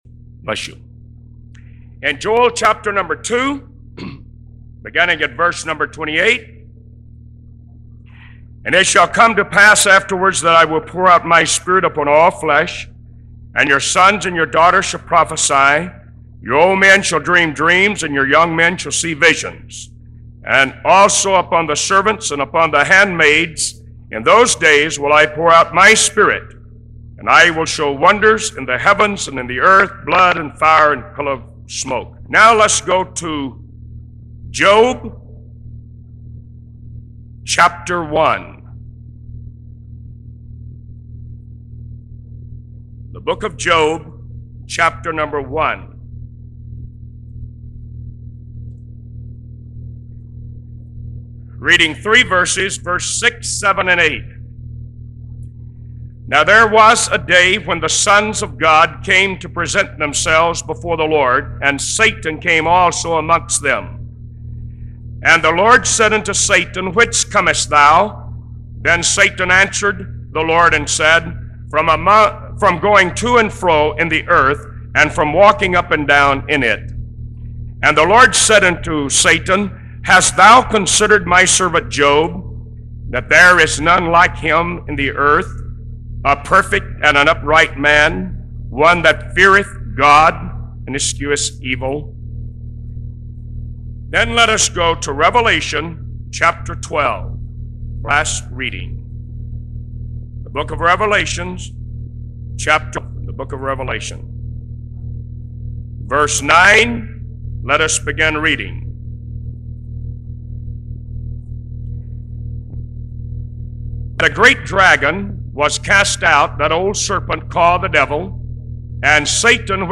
Featured Sermons